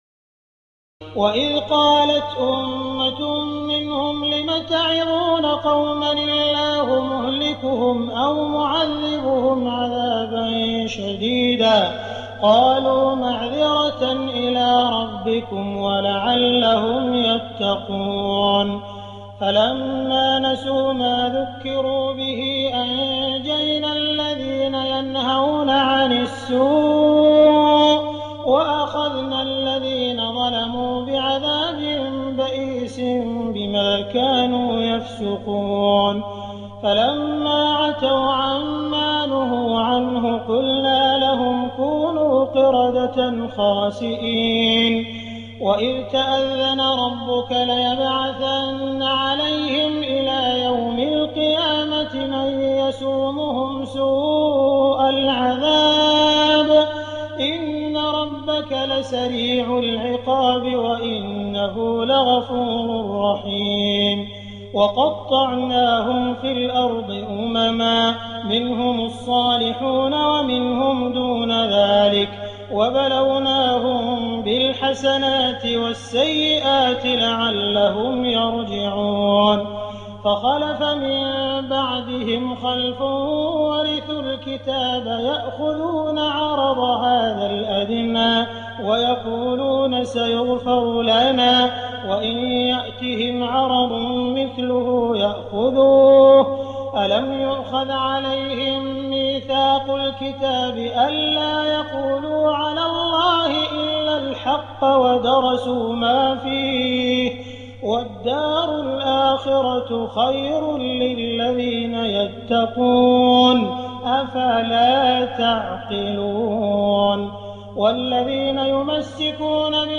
تراويح الليلة الثامنة رمضان 1419هـ من سورتي الأعراف (164-206) والأنفال (1-40) Taraweeh 8 st night Ramadan 1419H from Surah Al-A’raf and Al-Anfal > تراويح الحرم المكي عام 1419 🕋 > التراويح - تلاوات الحرمين